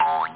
1 channel
boink.mp3